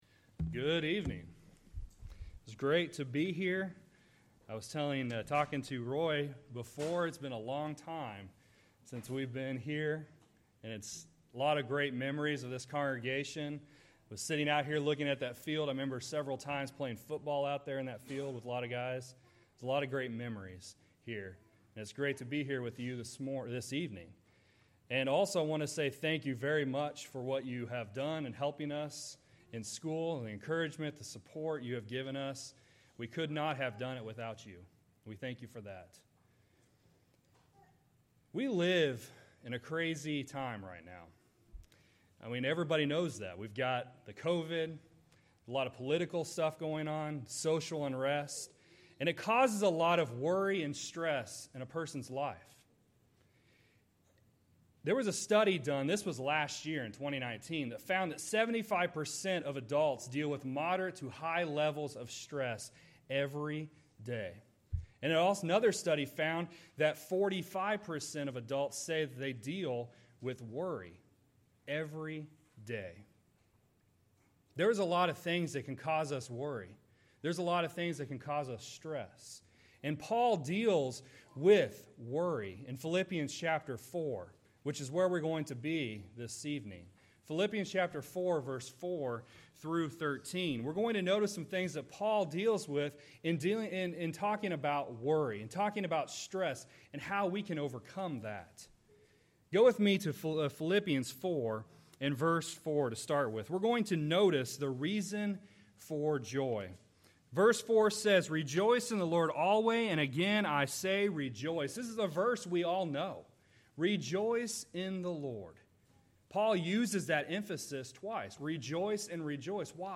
Philippians 4:4-13 Service Type: Sunday Evening Worship We live in a crazy time.